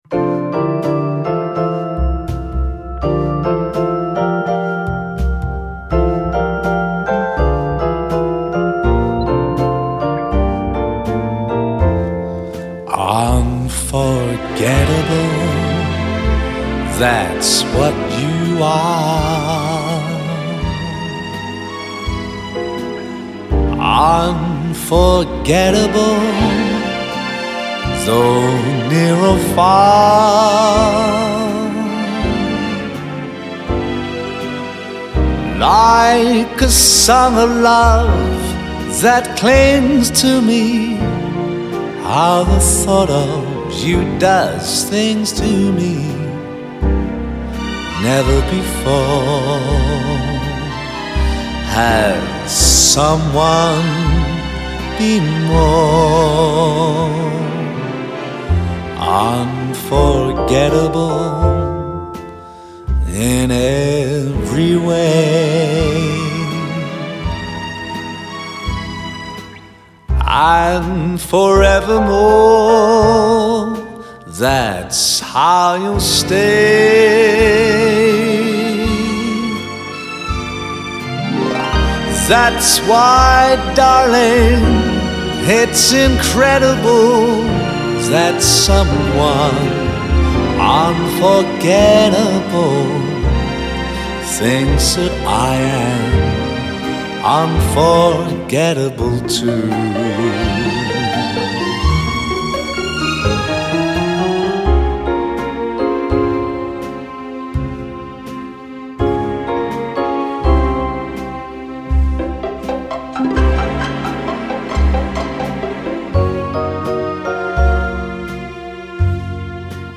and other great singers from the swing era like Tony Bennett